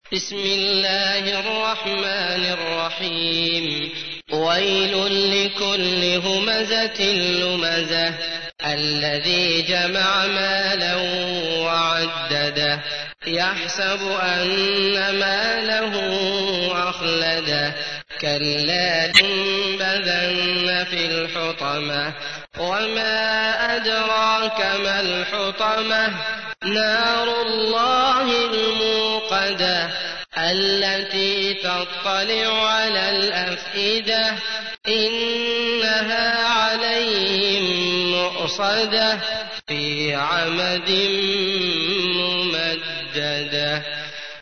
تحميل : 104. سورة الهمزة / القارئ عبد الله المطرود / القرآن الكريم / موقع يا حسين